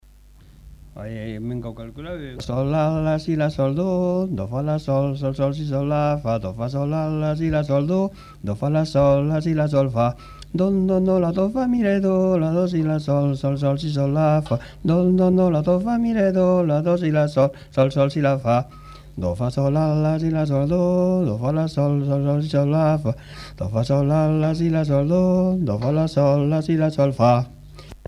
Mélodie (notes chantées)
Aire culturelle : Savès
Genre : chant
Effectif : 1
Type de voix : voix d'homme
Production du son : chanté
Danse : rondeau